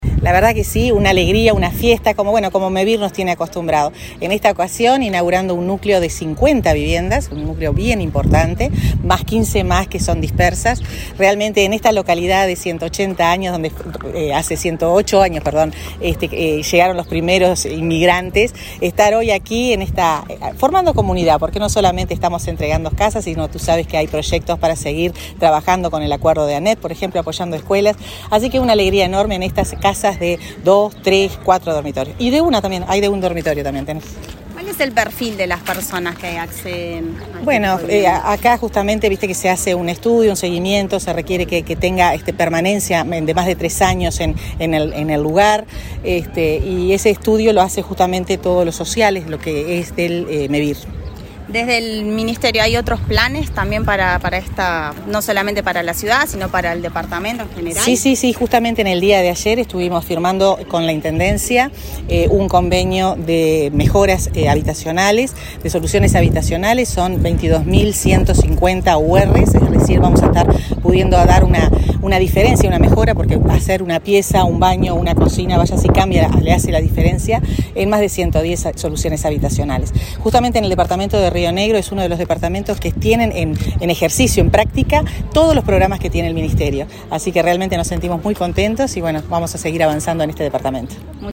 Declaraciones de la ministra de Vivienda, Irene Moreira